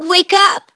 synthetic-wakewords
ovos-tts-plugin-deepponies_Cozy Glow_en.wav